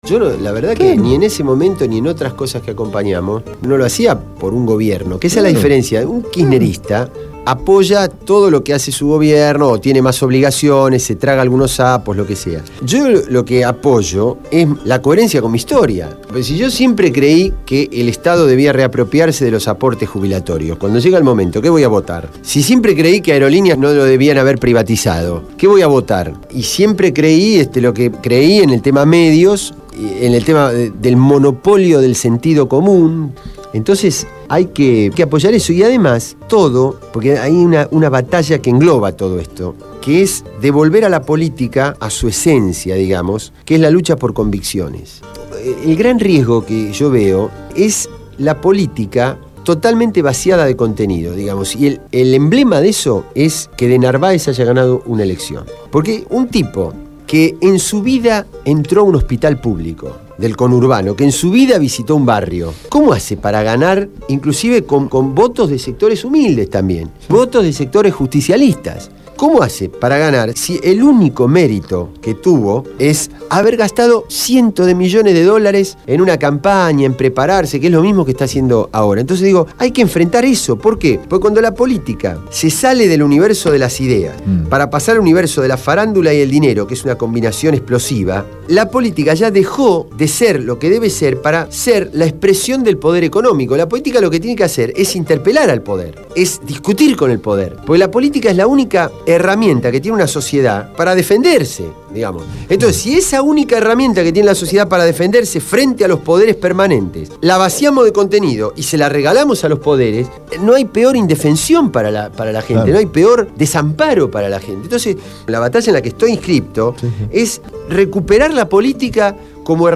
Carlos Raimundi, diputado del bloque Solidaridad e Igualdad (SI) estuvo en el piso de Radio Gráfica durante la emisión del programa «Cambio y Futuro» (Jueves, de 20 a 22 hs).